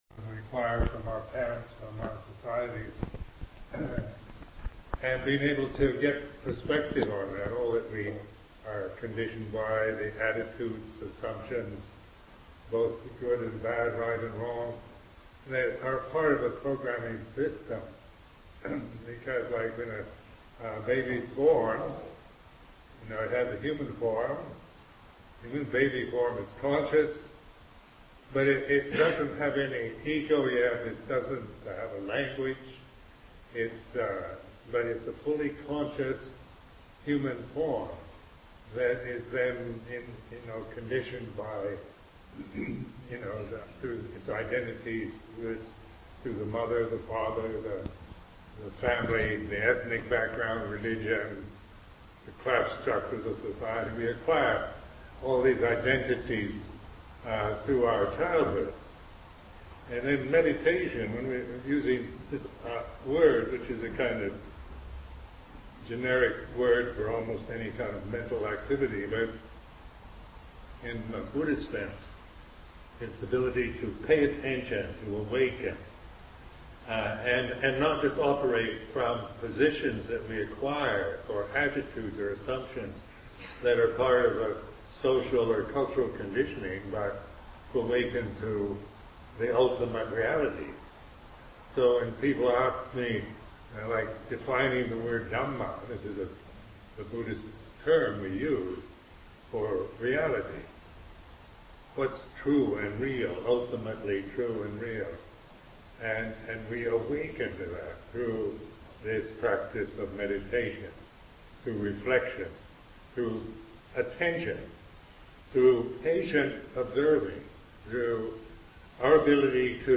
Teacher: Ajahn Sumedho Date: 2014-07-01 Venue: Seattle Insight Meditation Center Series [display-posts] Description (The recording begins as Ajahn Sumedho is already speaking.)
A Dharma Talk with Ajahn Sumedho